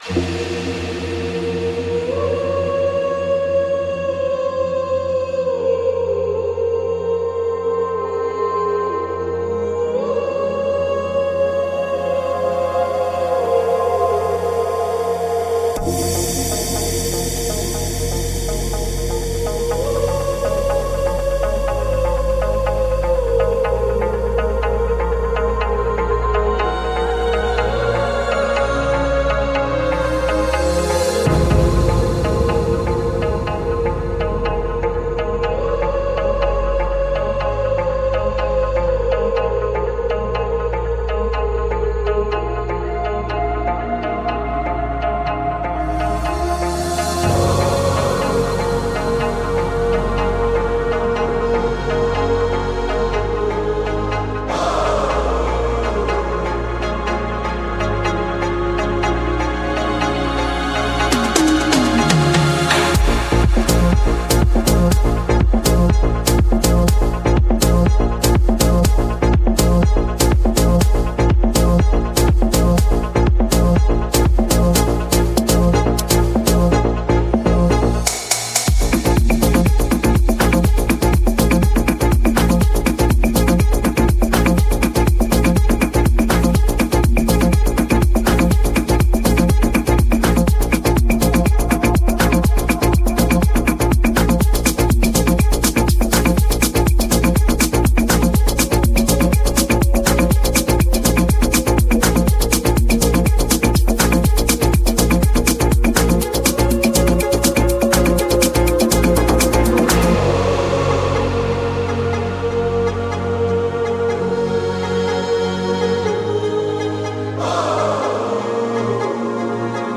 Genre: Progressive House